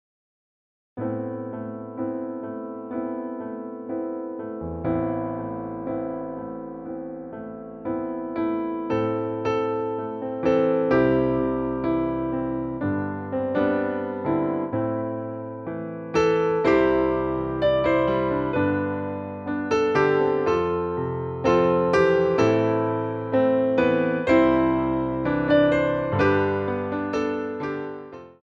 Plié on a 4/4